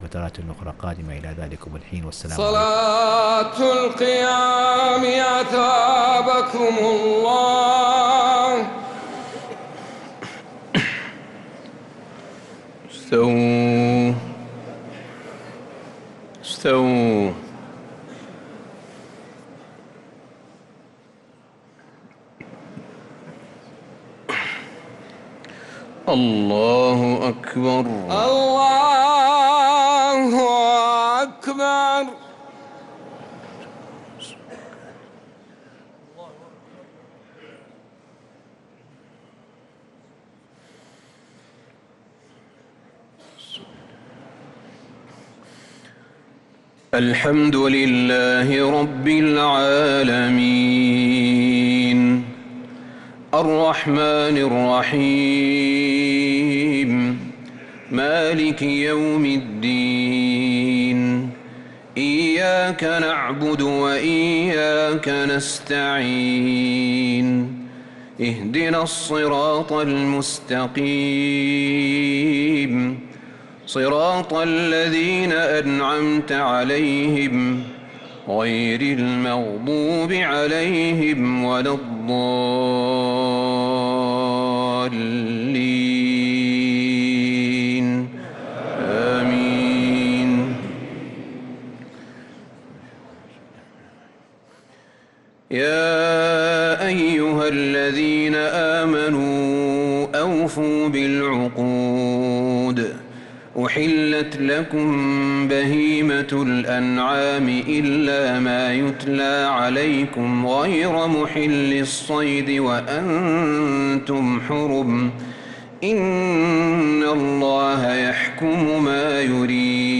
تراويح ليلة 8 رمضان 1446هـ من سورة المائدة {1-40} Taraweeh 8th night Ramadan 1446H Surah Al-Maidah > تراويح الحرم النبوي عام 1446 🕌 > التراويح - تلاوات الحرمين